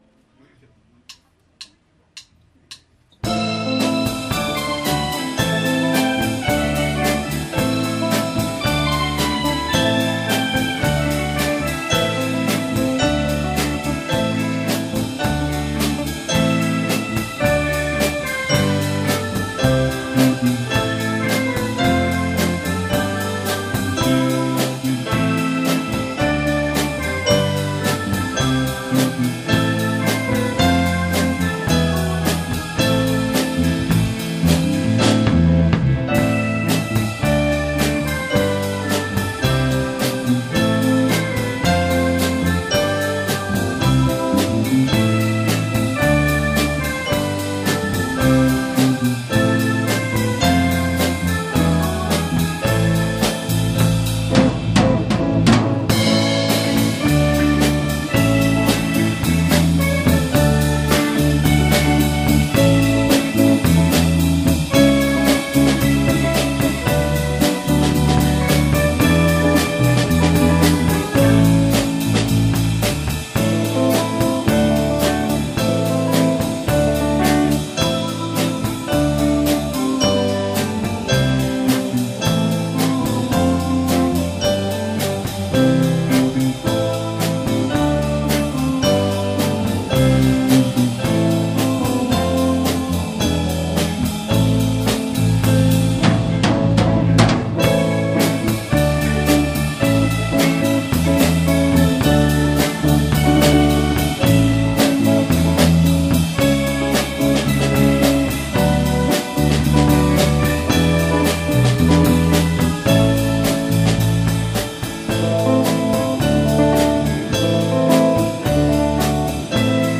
2018 Summer Live -2 | The Arou Can